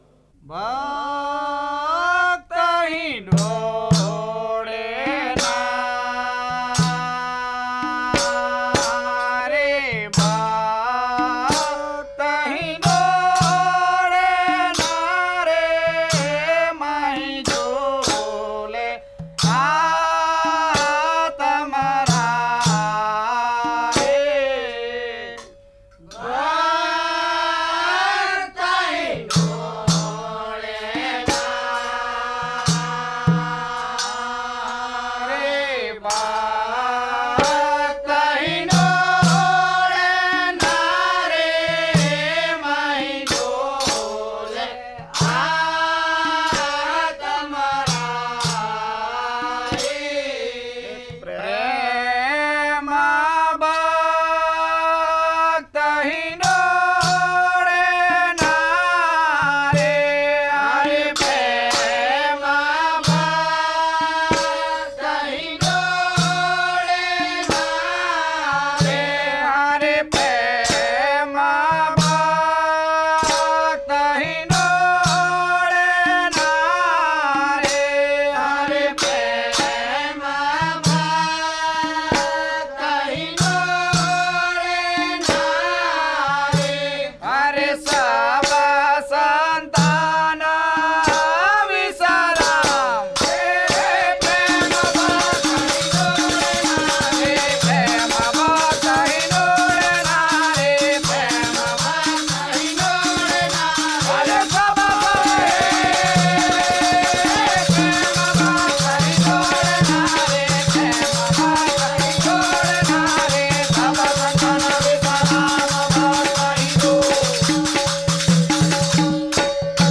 traditional bhajans